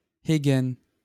Haegen (French pronunciation: [ɛɡən]